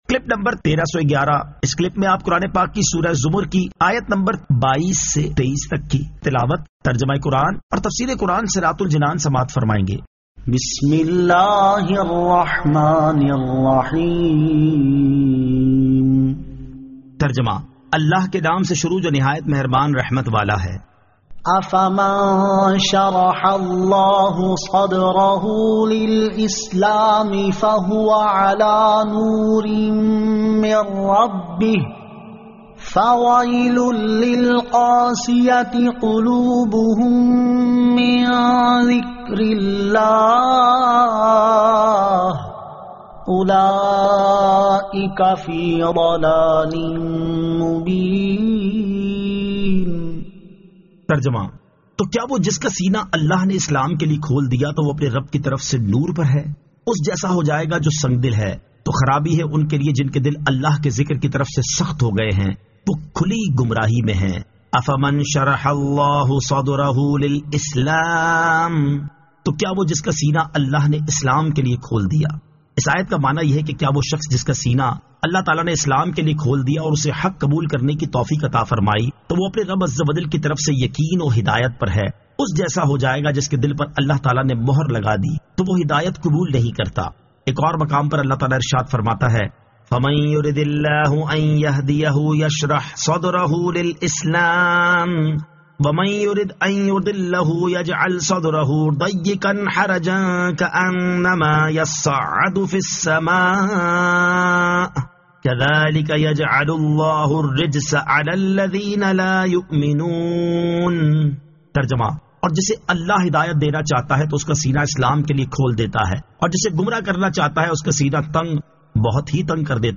Surah Az-Zamar 22 To 23 Tilawat , Tarjama , Tafseer